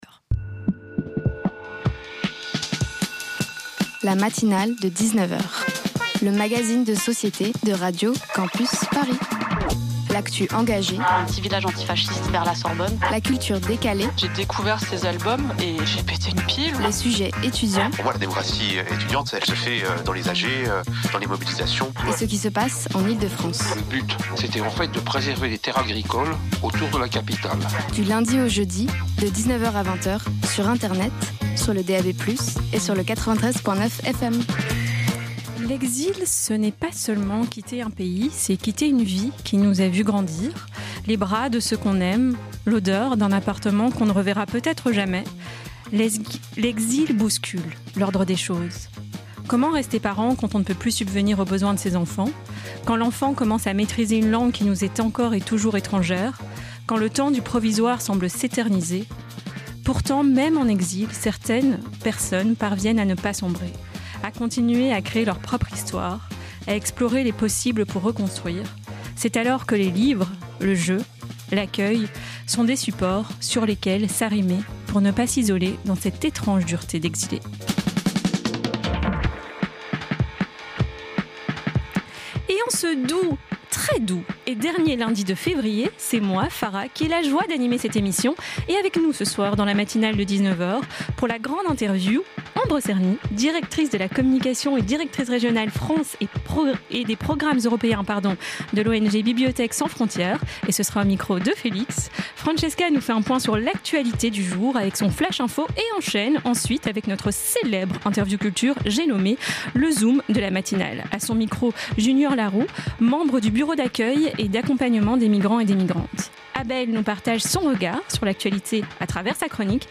L'éducation sous la guerre en Ukraine & l'association BAAM Partager Type Magazine Société Culture lundi 23 février 2026 Lire Pause Télécharger Ce soir